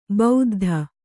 ♪ bauddha